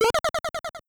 Sound effect of "Trampoline" in Super Mario Bros. Deluxe.
SMBDX_Trampoline.oga